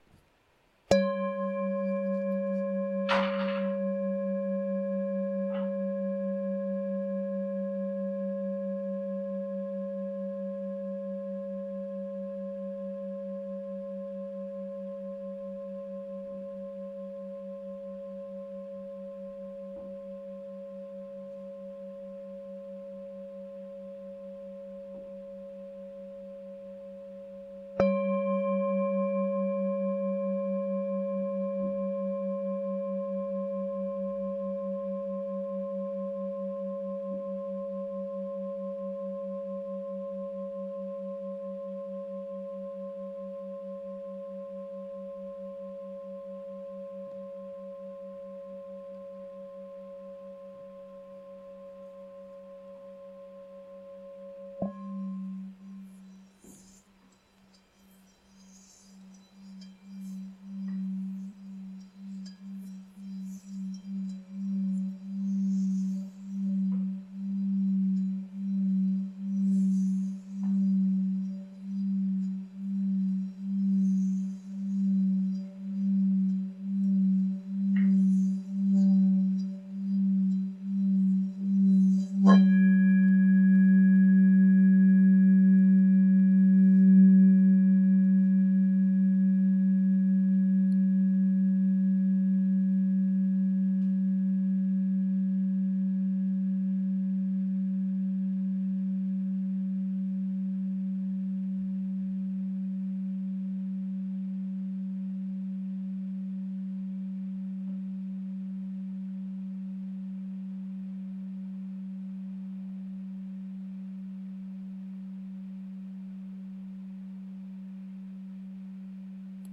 Nota Armonica DO(C) #5 552 HZ
Nota di fondo SOL(G) 3 192 HZ
Campana Tibetana Nota SOL(G) 3 192 HZ